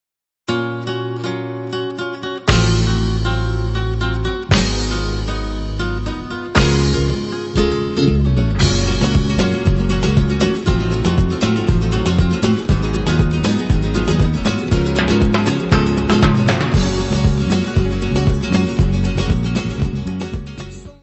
guitarra, voz e palmas
: stereo; 12 cm
Music Category/Genre:  World and Traditional Music